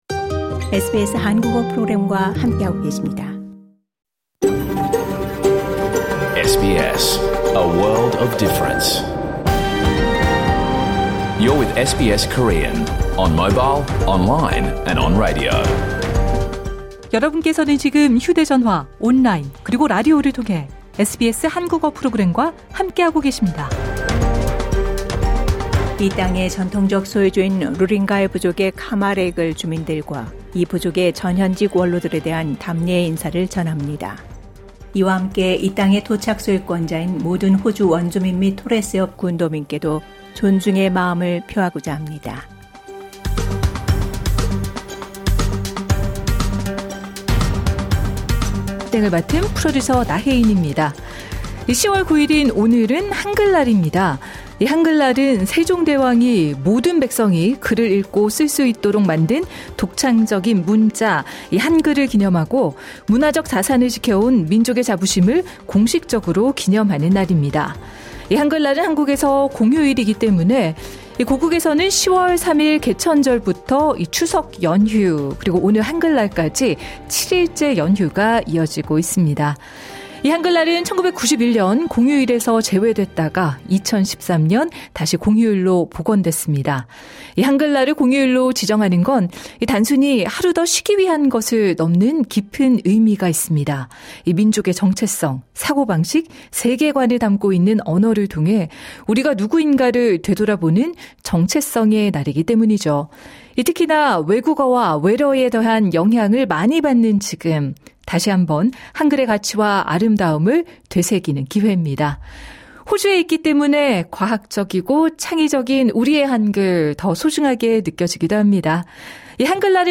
2025년 10월 9일 목요일에 방송된 SBS 한국어 프로그램 전체를 들으실 수 있습니다.